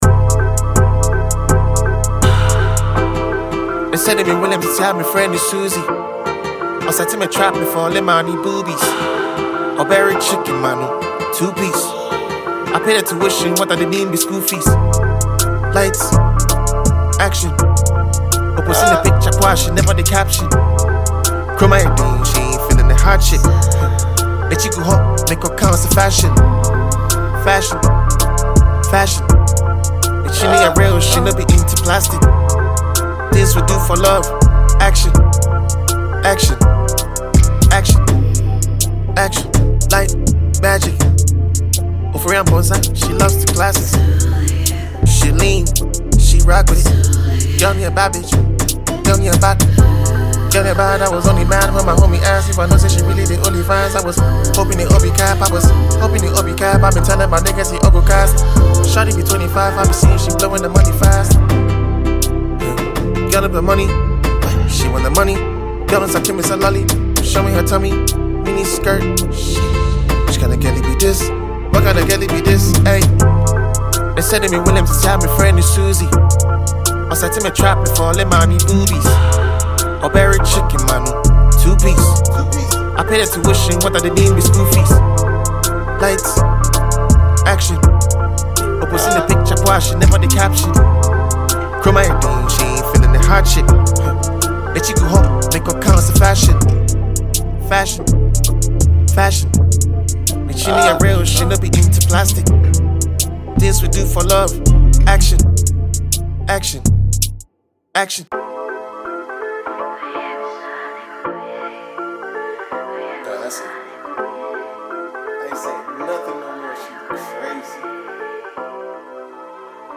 Ghanaian hip-hop and Afrobeats icon
deeply emotional and reflective track